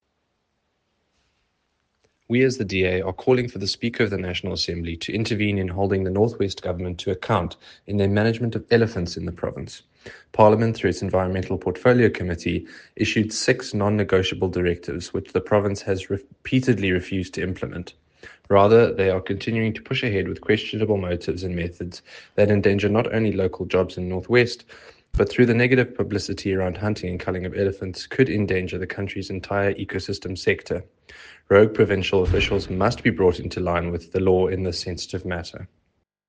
soundbite by Andrew de Blocq MP.